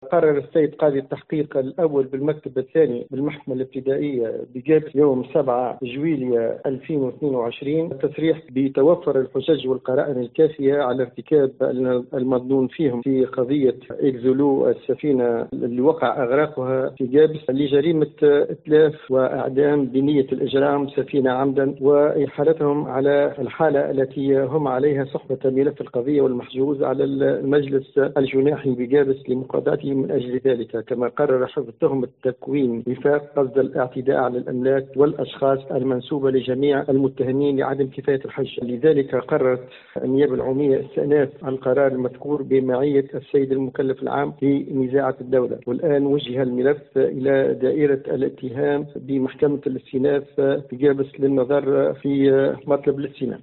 أفاد وكيل الجمهورية بالمحكمة الابتدائية بقابس والناطق الرسمي باسمها، محمد الكراي، في تصريح ل’ام اف ام” اليوم بأن قاضي التحقيق الأول بالمكتب الثاني بالمحكمة الابتدائية بقابس أكد يوم 7 جويلية الجاري توفر الحجج والقرائن التي اثبتت إرتكاب المتهمين في قضية السفينة الاجنبية إغراقها خلال المدة الفارطة في مياه بحر قابس .